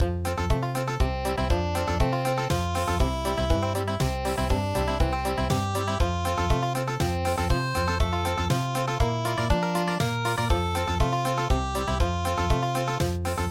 カントリー風